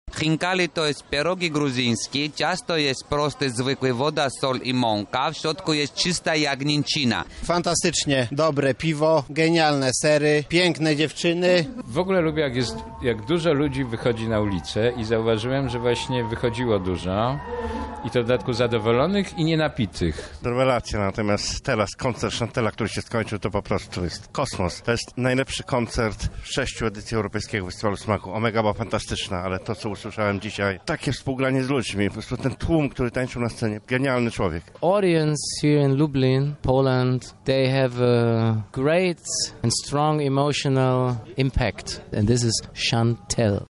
Na miejscu był nasz reporter